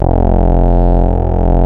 OSCAR F1 5.wav